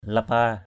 /la-pa:/ (cv.) lipa l{p% (t.) đói = avoir faim. hunger, hungry. lapa tian l{p% t`N đói bụng = avoir faim. be hungry. panal lapa pqL lp% lót dạ = prendre...
lapa.mp3